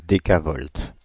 Ääntäminen
IPA: [de.ka.vɔlt]